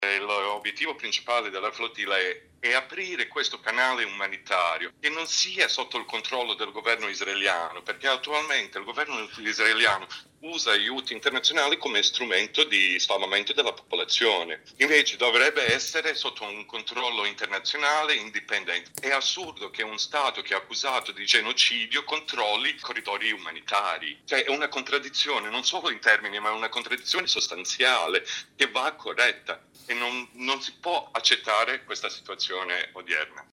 operatore umanitario